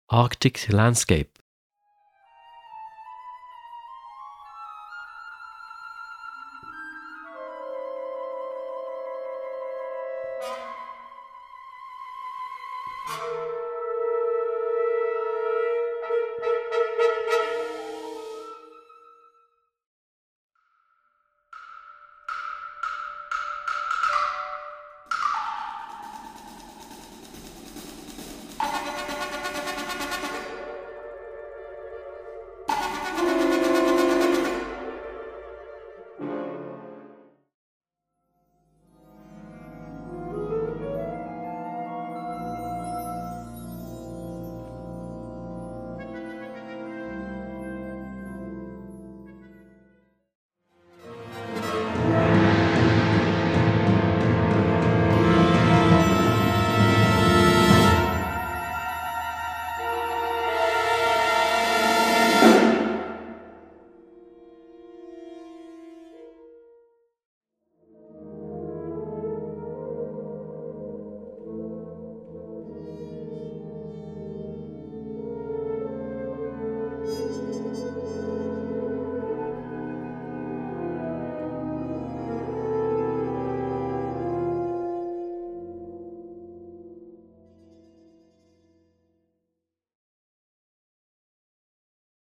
Gattung: Konzertwerk
17:23 Minuten Besetzung: Blasorchester Tonprobe